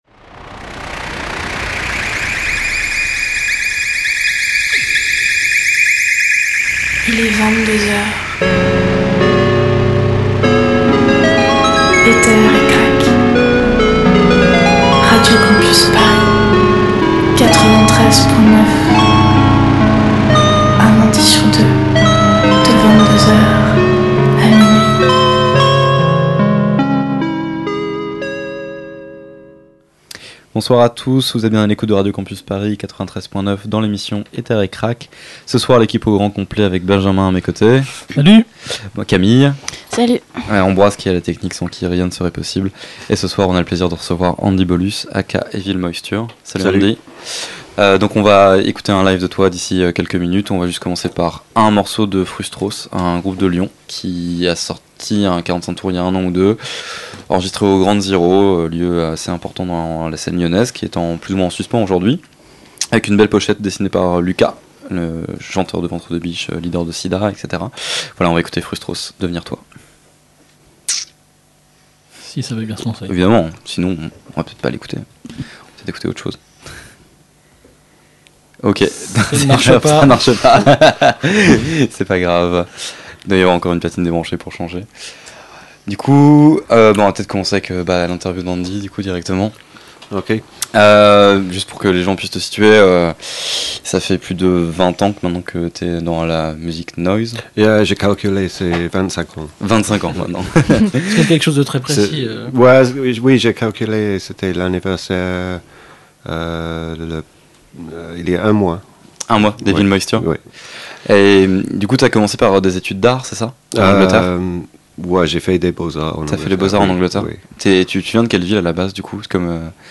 Au programme : un live de près de quarante minutes, une interview et une courte sélection de notre invité.